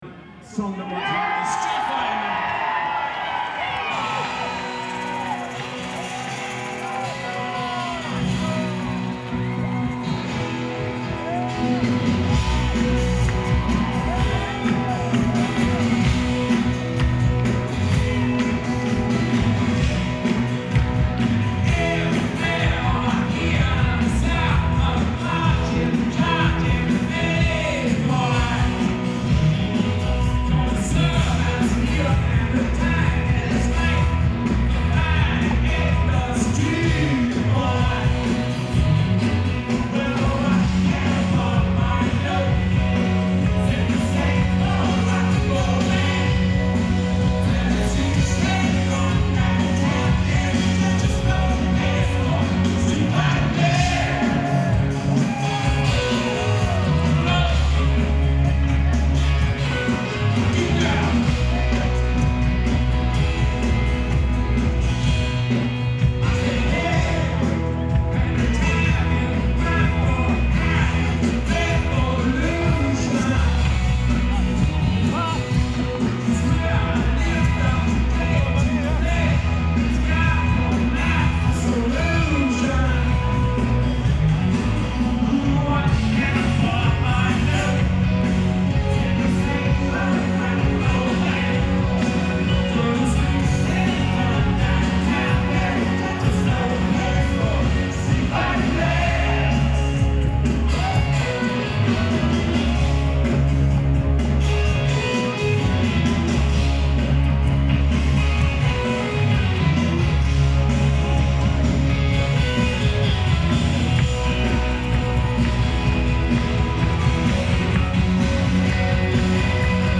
fan recordings matrixed. mix so far.
Rock & Roll